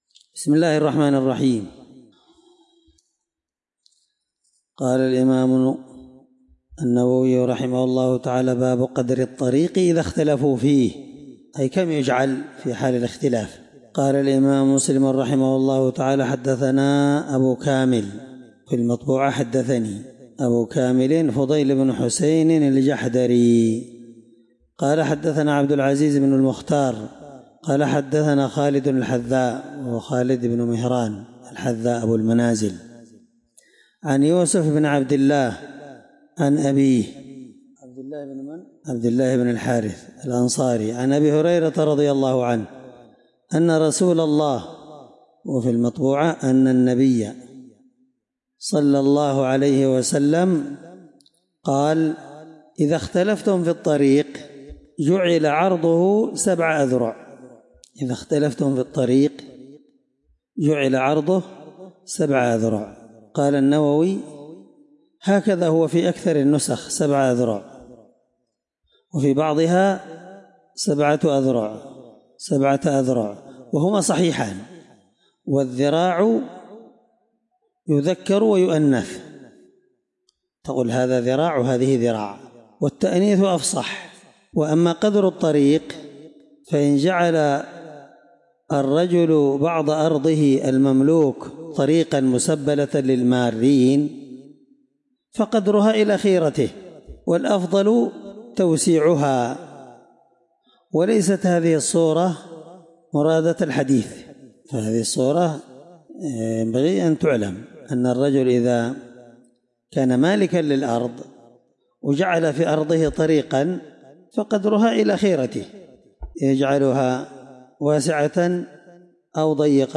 الدرس38من شرح كتاب المساقاة حديث رقم(1613) من صحيح مسلم